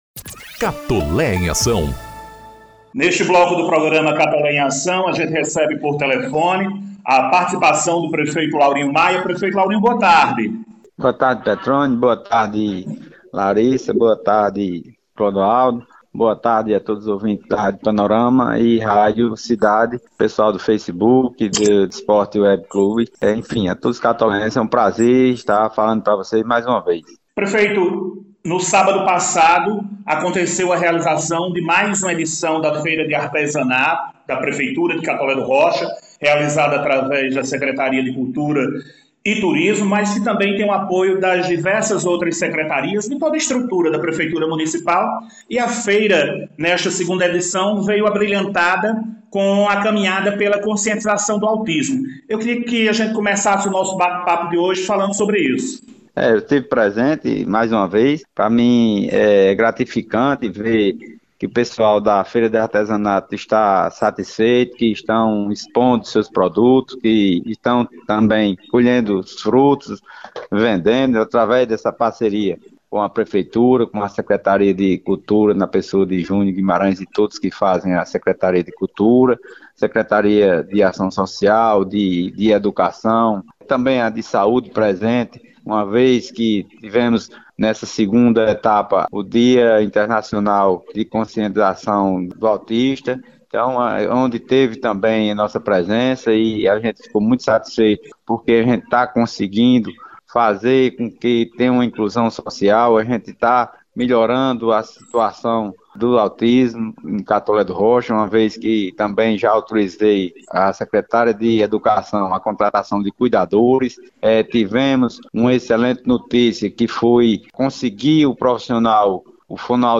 O prefeito de Catolé do Rocha – Laurinho Maia – concedeu entrevista ao Programa ‘Catolé em Ação’, na sexta-feira (08/04), oportunidade em que comentou sobre diversos assuntos.